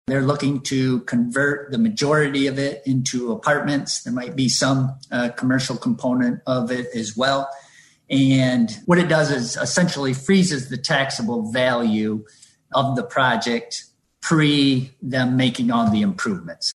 According to Zeeland City Manager Tim Klunder, Geenen DeKock Properties bought the structure last October.